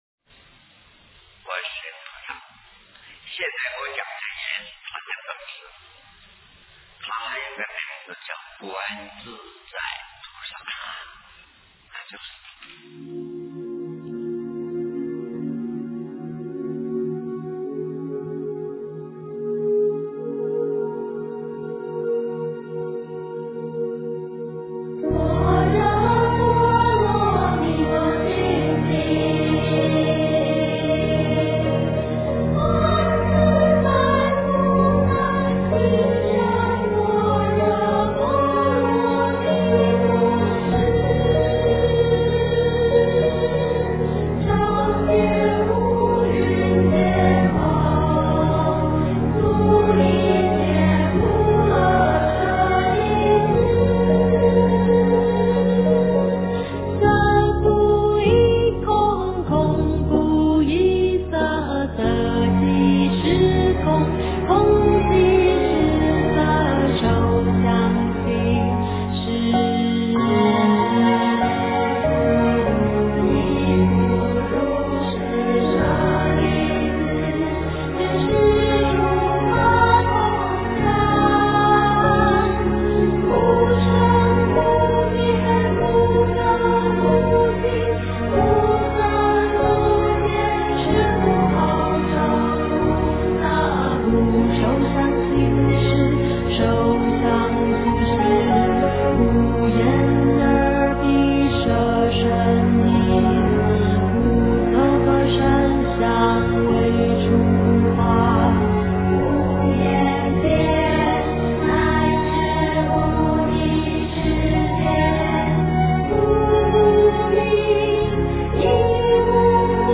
心经 诵经 心经--南怀瑾文教基金会 点我： 标签: 佛音 诵经 佛教音乐 返回列表 上一篇： 心经-梵音 下一篇： 大悲咒 相关文章 大方广佛华严经华严普贤行愿忏-下--僧团 大方广佛华严经华严普贤行愿忏-下--僧团...